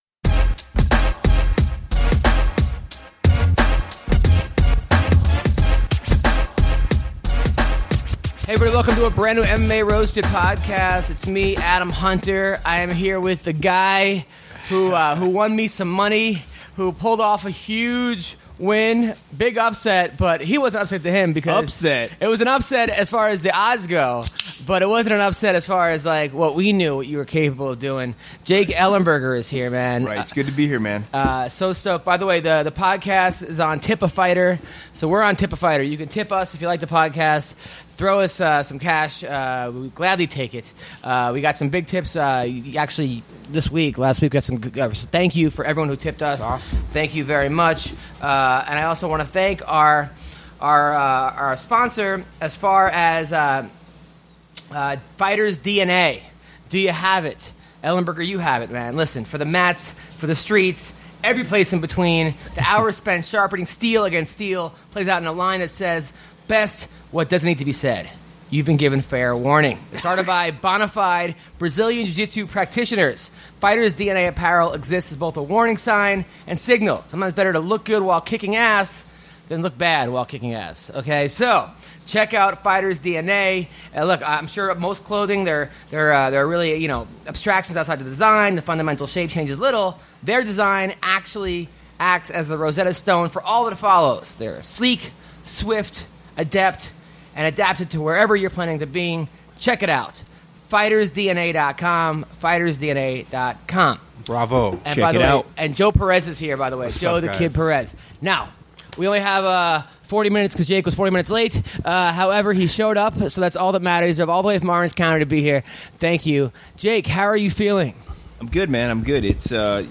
Jake Ellenberger stops by the studio to talk about his future in the UFC and his thoughts on the upcoming Condit Vs. Maia Fight Night.